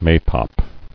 [may·pop]